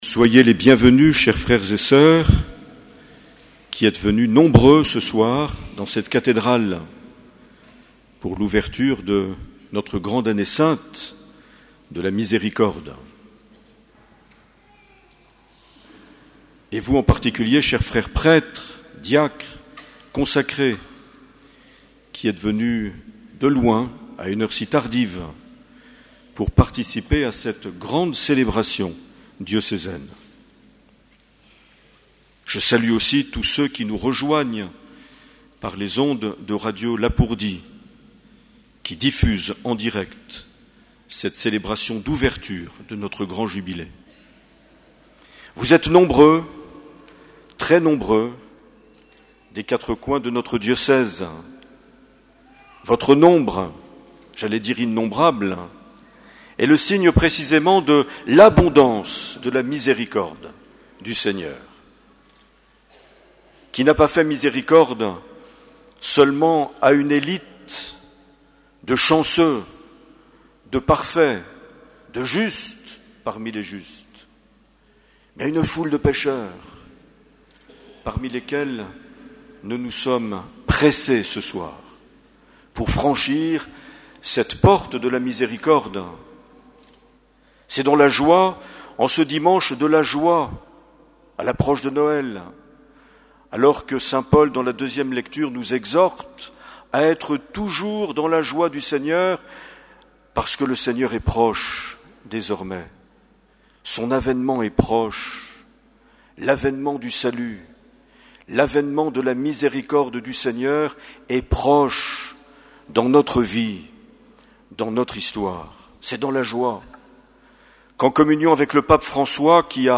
13 décembre 2015 - Cathédrale de Bayonne - Messe d’ouverture du Jubilé de la Miséricorde
Accueil \ Emissions \ Vie de l’Eglise \ Evêque \ Les Homélies \ 13 décembre 2015 - Cathédrale de Bayonne - Messe d’ouverture du Jubilé de la (...)
Une émission présentée par Monseigneur Marc Aillet